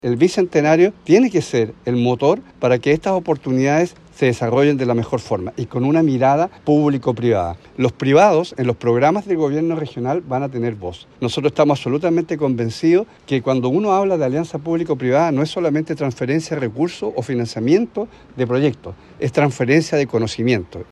El gobernador regional, Alejandro Santana, y la delegada presidencial, Paulina Muñoz, participaron en la reunión y resaltaron la importancia de un trabajo coordinado entre el sector público y privado para el éxito de la implementación de la estrategia.
gobernador-sant.mp3